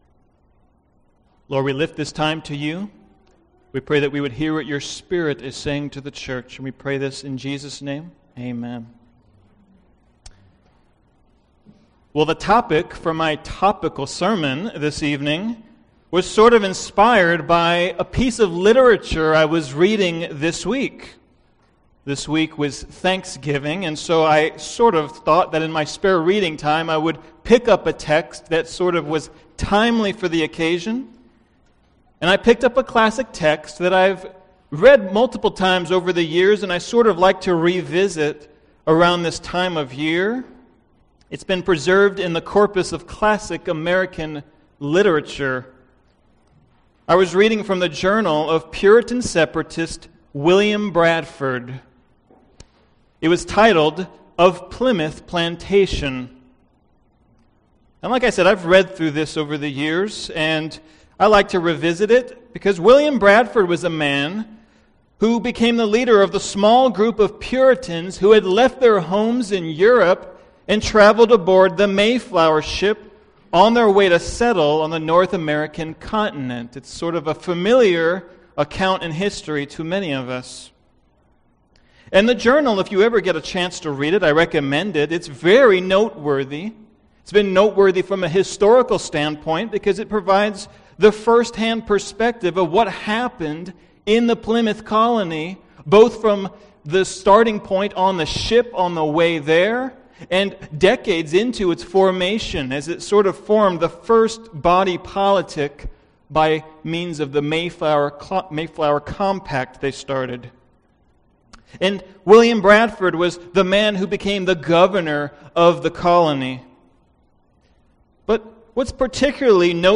Podcast (heritage-valley-bible-church-sermons): Play in new window | Download